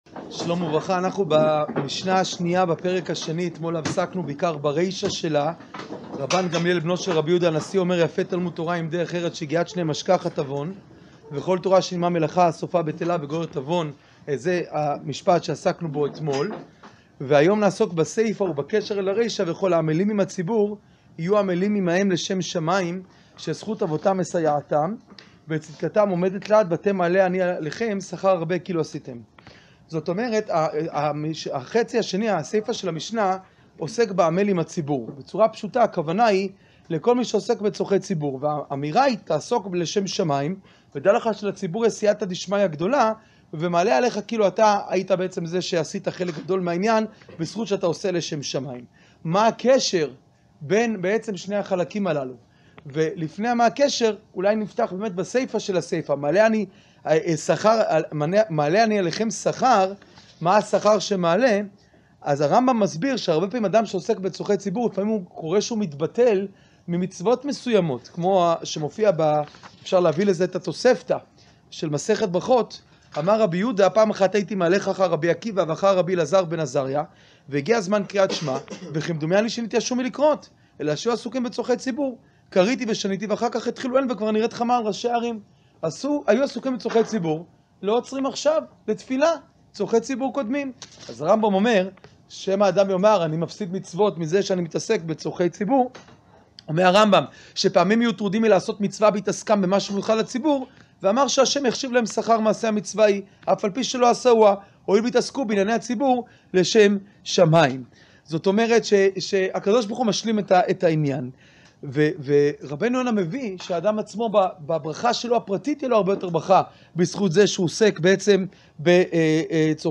שיעור פרק ב משנה ב